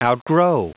Prononciation du mot outgrow en anglais (fichier audio)
Prononciation du mot : outgrow